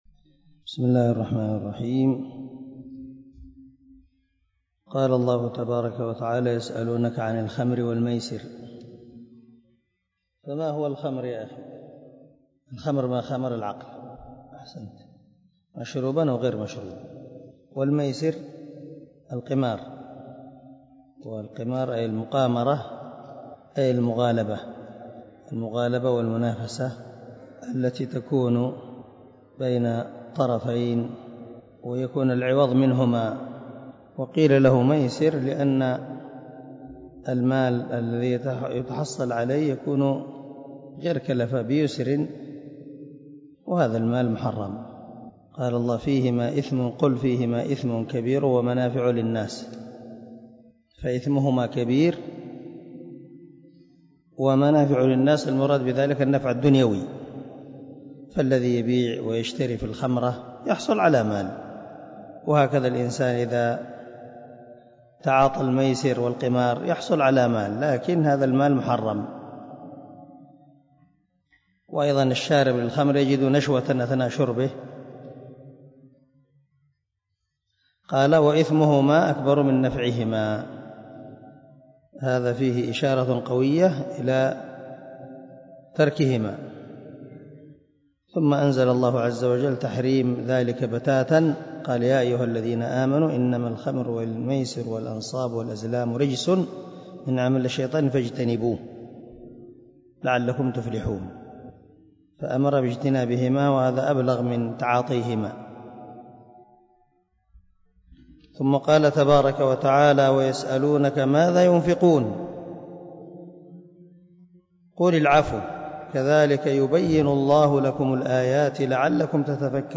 109الدرس 99 تفسير آية ( 220 ) من سورة البقرة من تفسير القران الكريم مع قراءة لتفسير السعدي